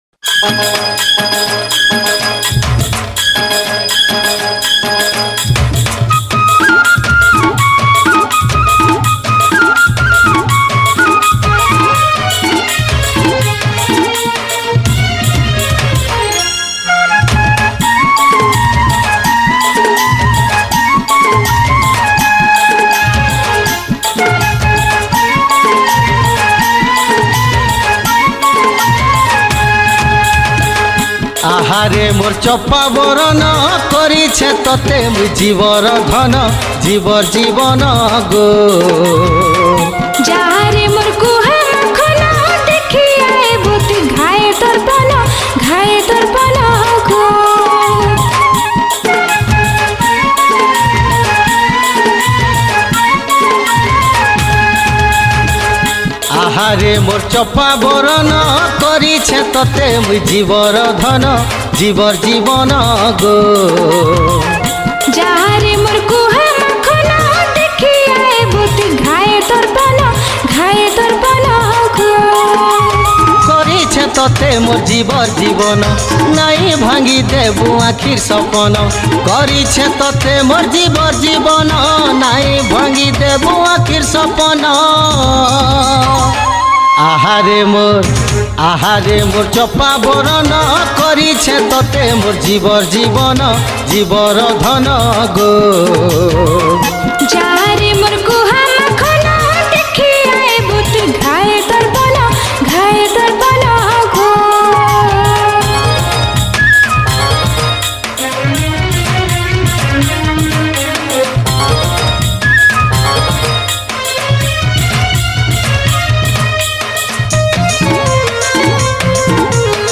Category: New Sambalpuri Folk Song 2021